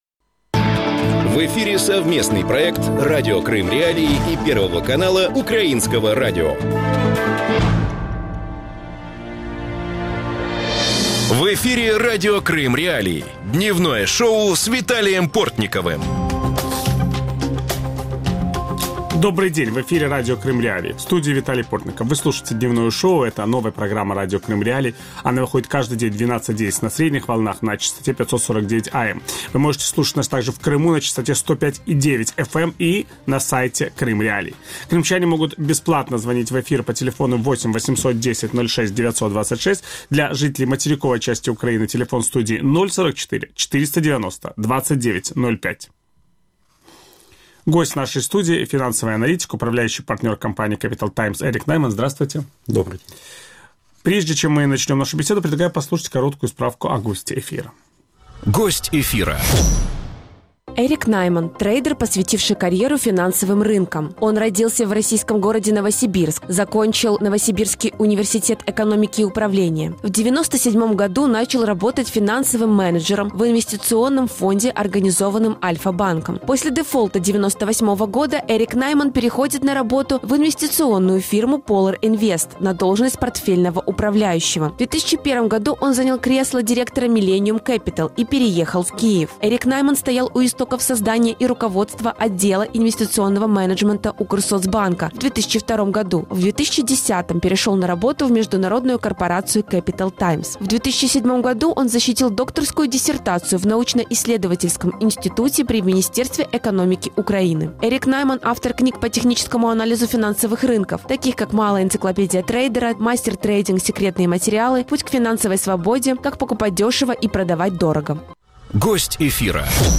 Ведущий – Виталий Портников.